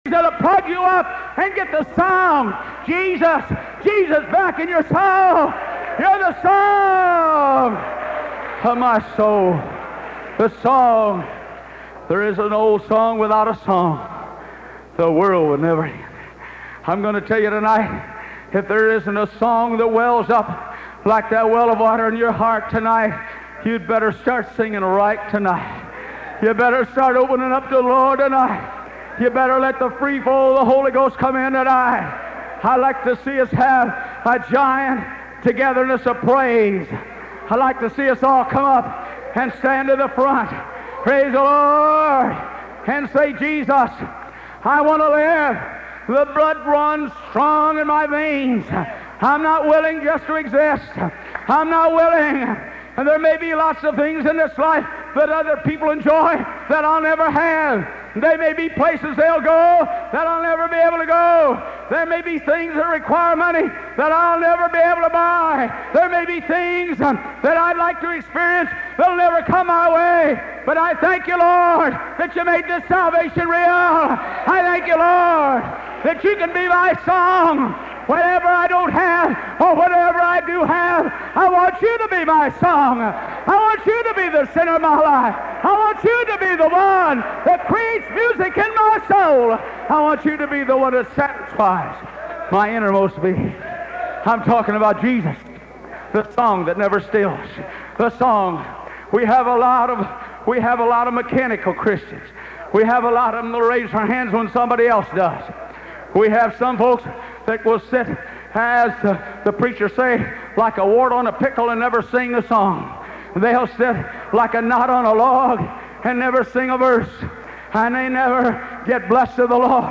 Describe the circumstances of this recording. This sermon was converted by a listener into four 7 minute segments.